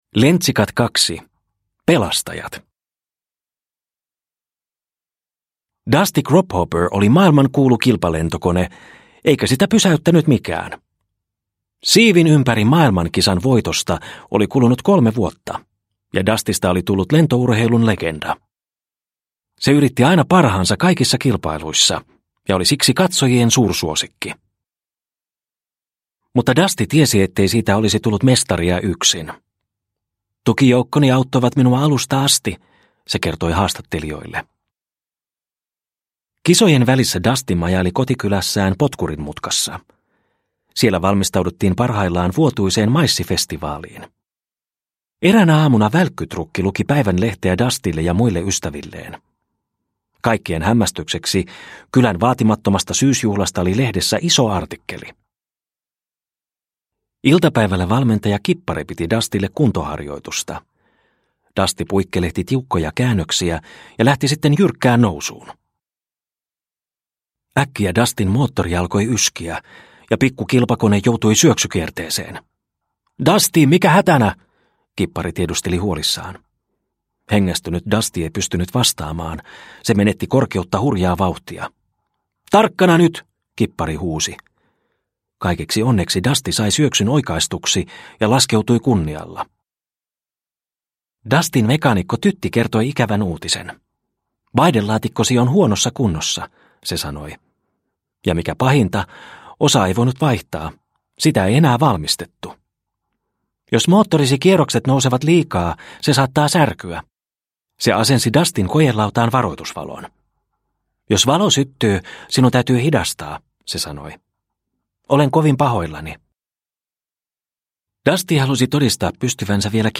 Lentsikat 2 – Ljudbok – Laddas ner